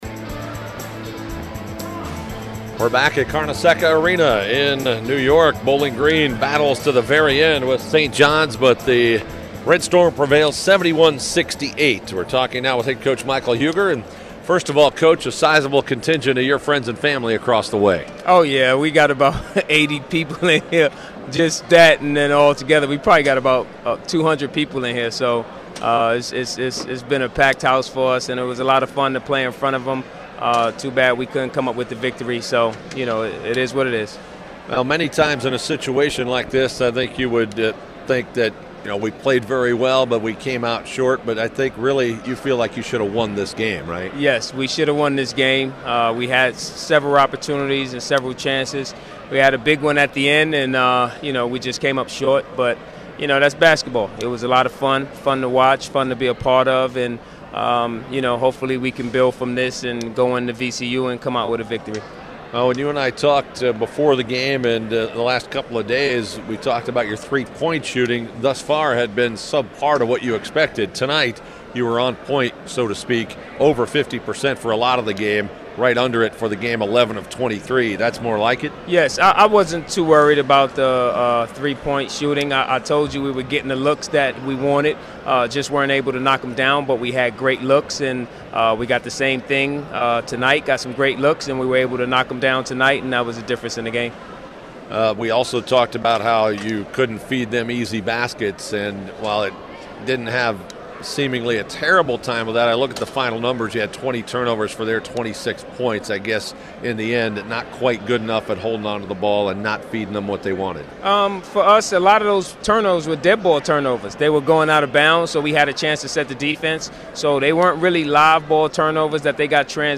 Post-Game Audio:
SJU Coaches postgame comments.mp3